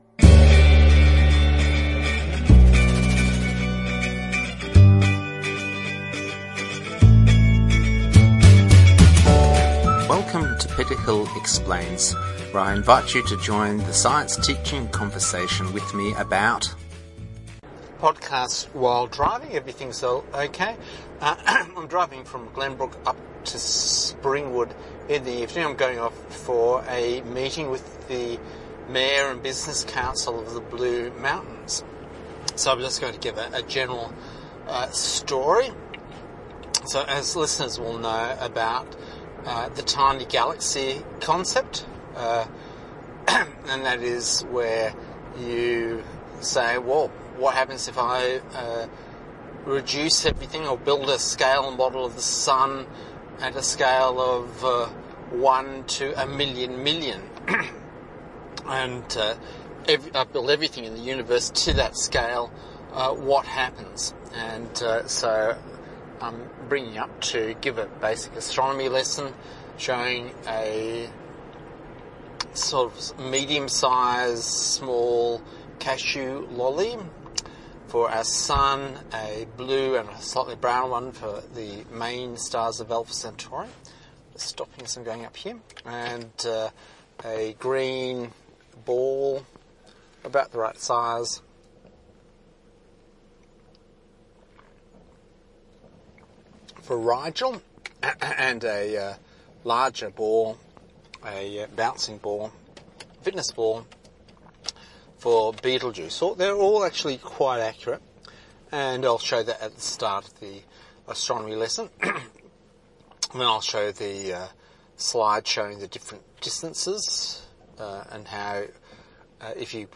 A podcast while driving so the quality is not the best.